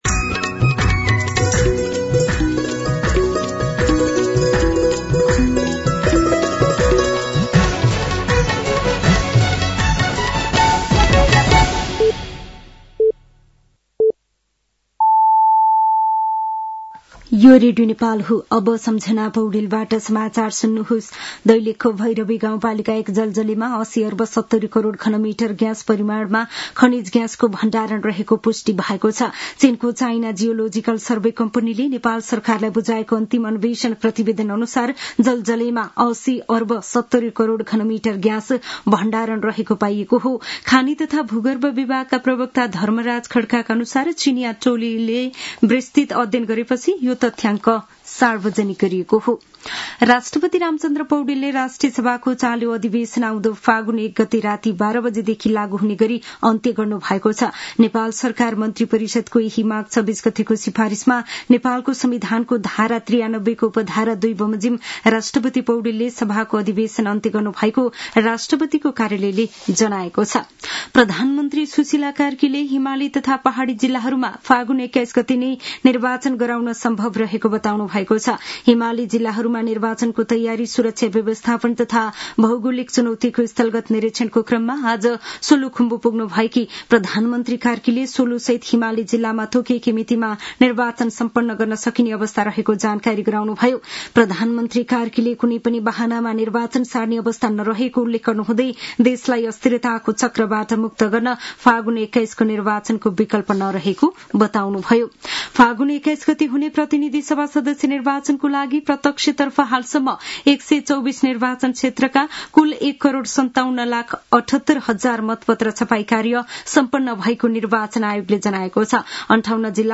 An online outlet of Nepal's national radio broadcaster
साँझ ५ बजेको नेपाली समाचार : २९ माघ , २०८२